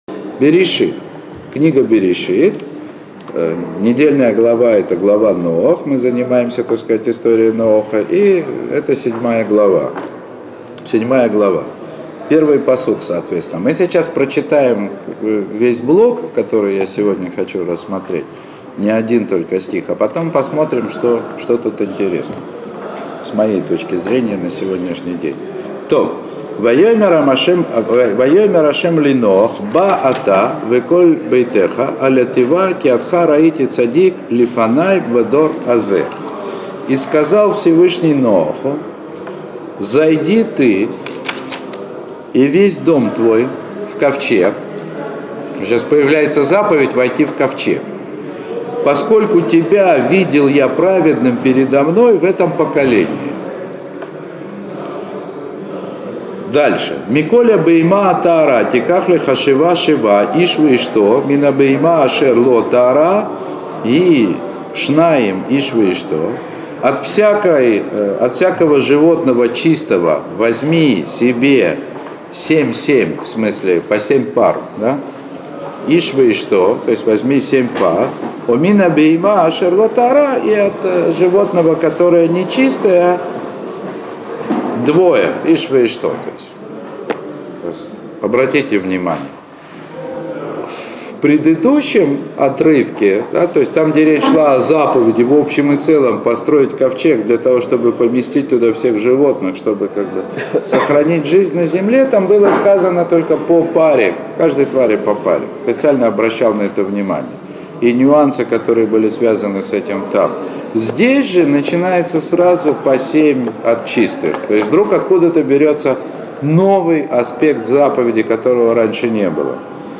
Уроки по книге Берейшит.